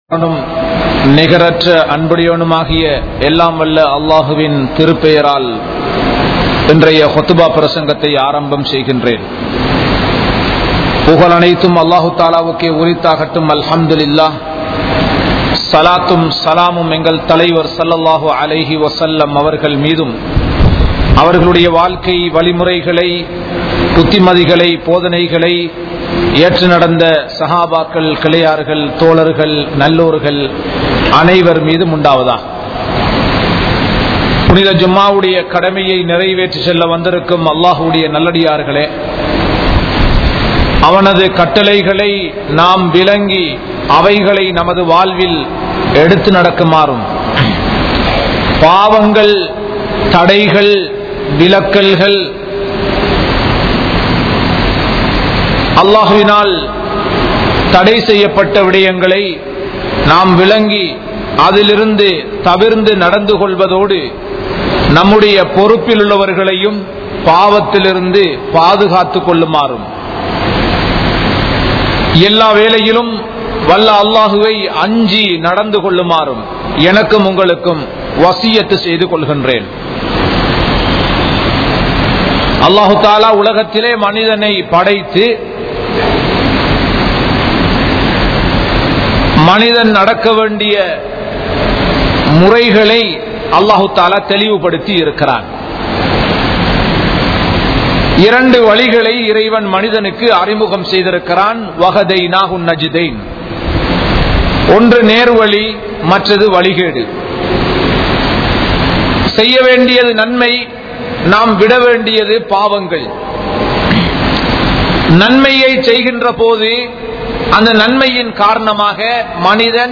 Paavaththin Vilaivuhal (பாவத்தின் விளைவுகள்) | Audio Bayans | All Ceylon Muslim Youth Community | Addalaichenai